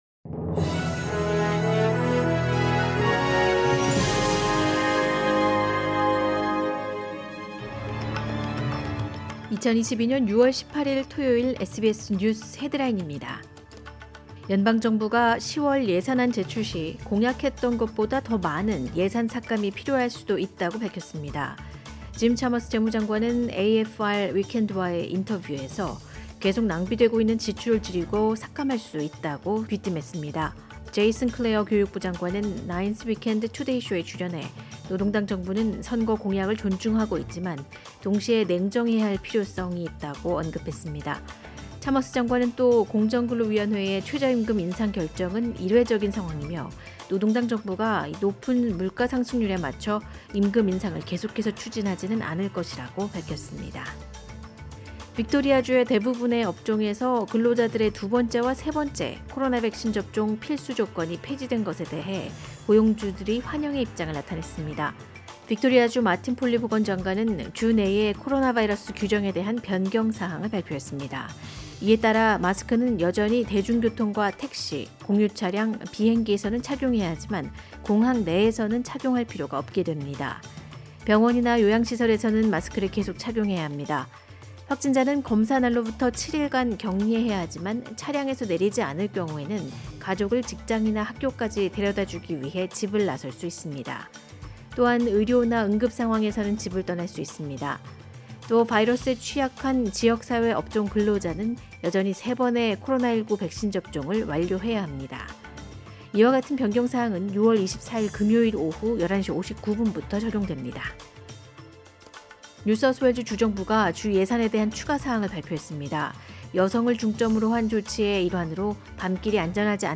2022년 6월 18일 토요일 SBS 한국어 간추린 주요 뉴스입니다.